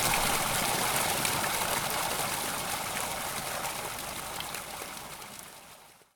fountain.ogg